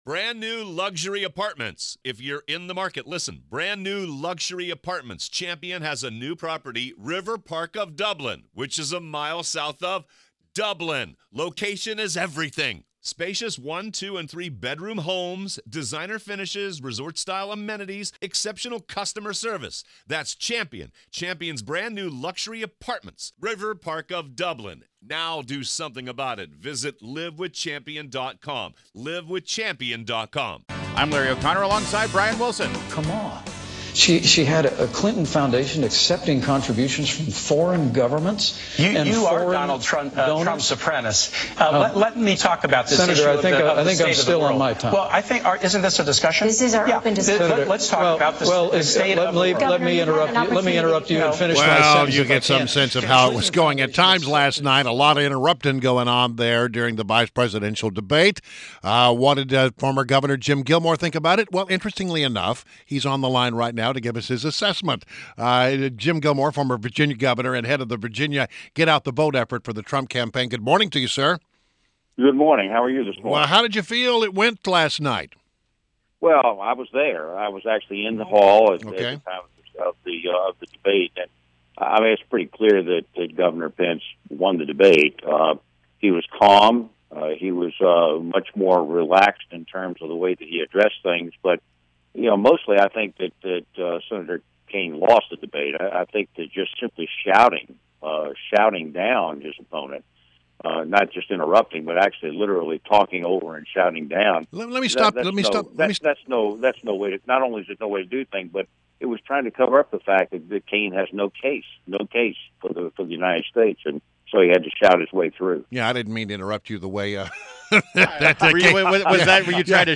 WMAL Interview - Fmr VA Governor JIM GILMORE - 10/5/16
INTERVIEW — GOV. JIM GILMORE – FORMER VIRGINIA GOVERNOR AND HEAD OF THE VIRGINIA GET-OUT-THE-VOTE FOR THE TRUMP CAMPAIGN – shared his thoughts on the VP debate.